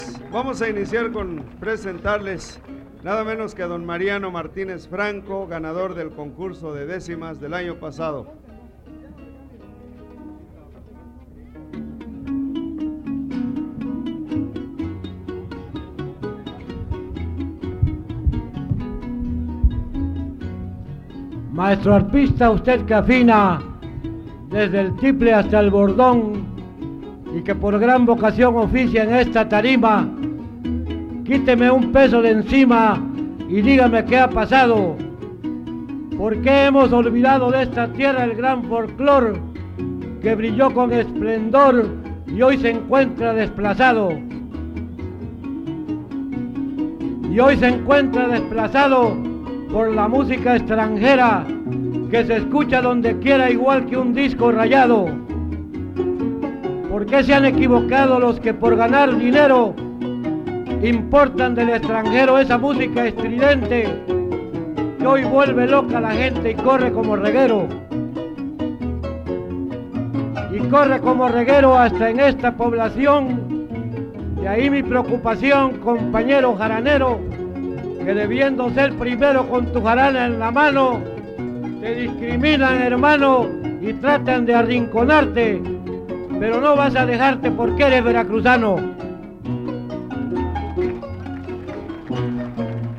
03 Maestro arpista Público